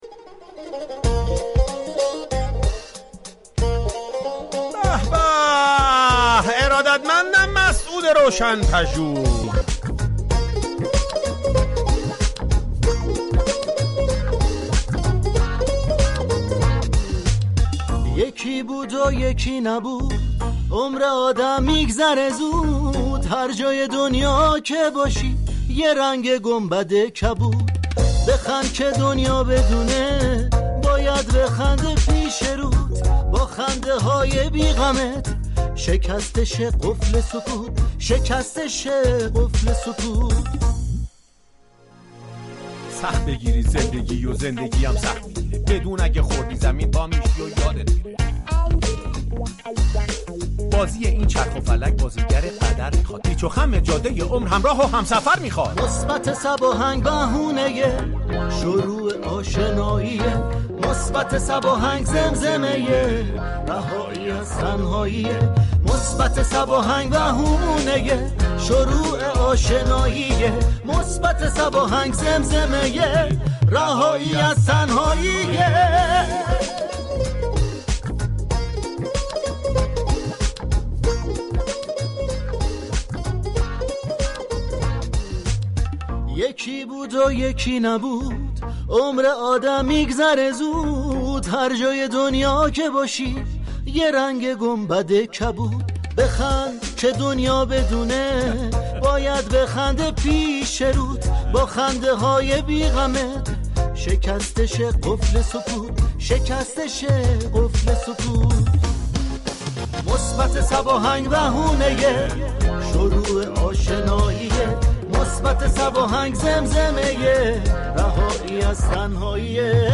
به گزارش روابط عمومی رادیو صبا ، "مثبت صباهنگ " كه در قالب جنگی عصرگاهی بر پایه موسیقی و گفتگوی صمیمی و طنز راهی آنتن می شود ، روزهای پنج شنبه و جمعه همراه مخاطبان می شود
«پخش قطعات خاطره انگیز» ،«ارتباط با خواننده ها» و «اجرای قطعات طنز » از دیگر بخش‌های این برنامه است.